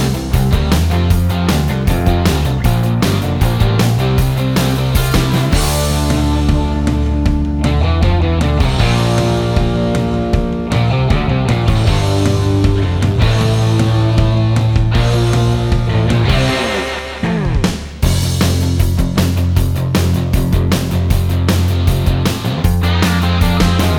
No Backing Vocals Soundtracks 3:38 Buy £1.50